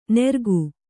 ♪ nergu